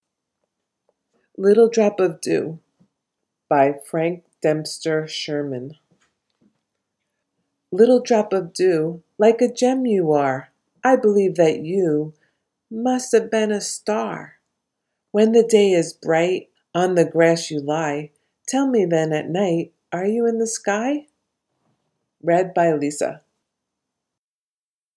Little Drop of Dew Poem Little drop of dew, Like a gem you are ; I believe that you Must have been a star.